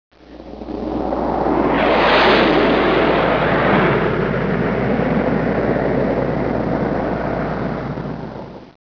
flyby.wav